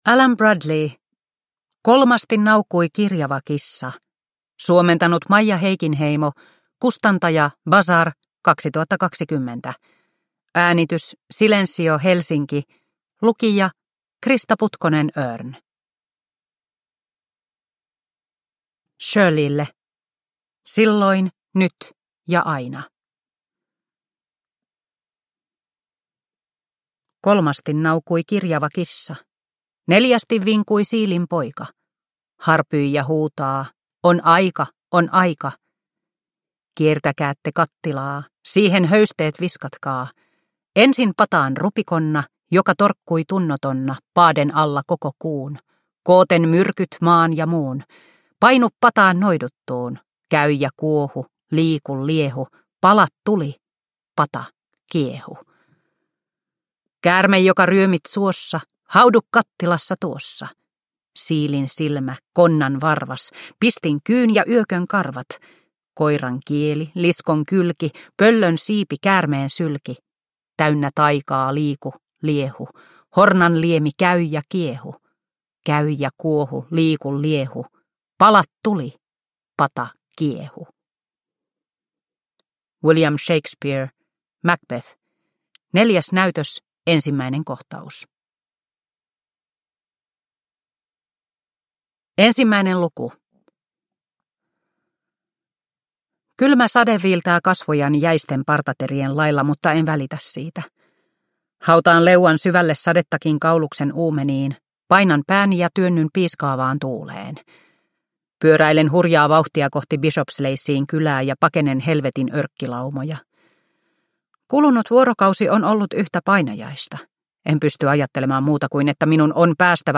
Kolmasti naukui kirjava kissa – Ljudbok – Laddas ner